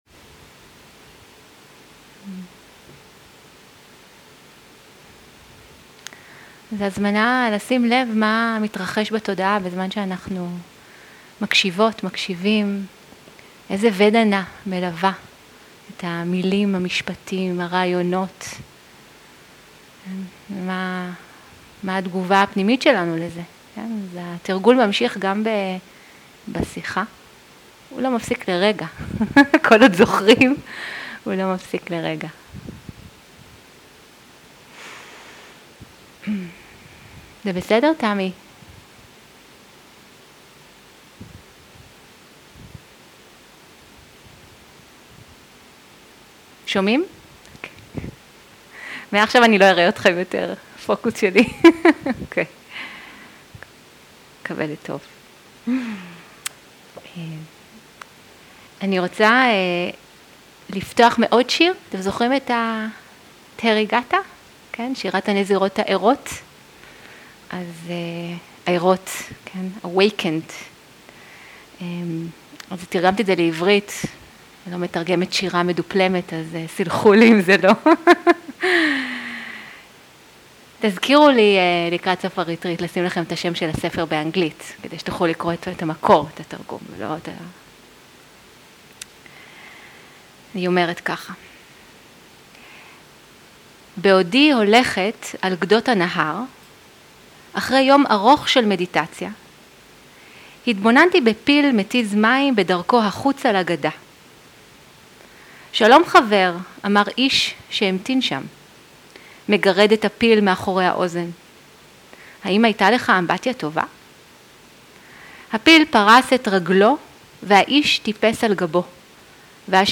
שיחת דהרמה
סוג ההקלטה: שיחות דהרמה
איכות ההקלטה: איכות גבוהה